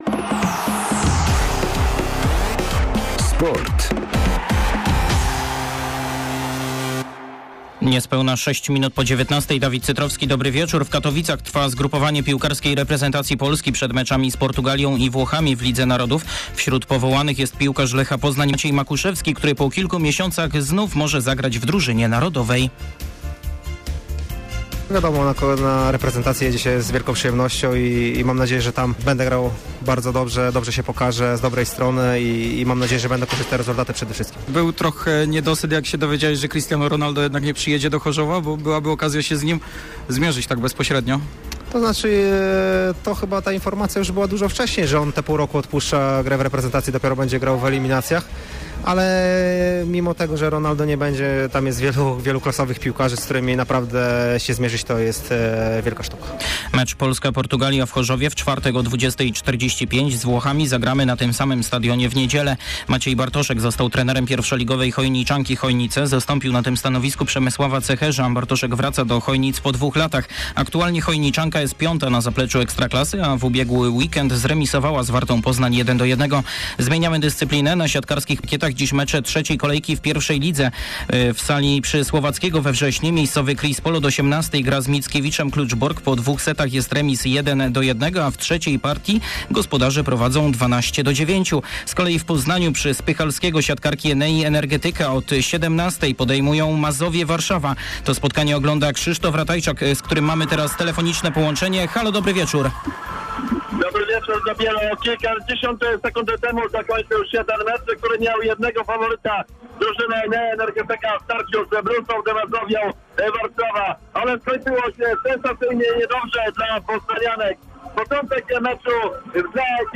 10.10. serwis sportowy godz. 19:05
W naszym środowym serwisie Maciej Makuszewski o meczu z Portugalią, a koszykarze Stali Ostrów Wielkopolski zapowiadają starcie z Legią Warszawa. Ponadto relacja live ze spotkania Enei Energetyka Poznań z Mazovią Warszawa.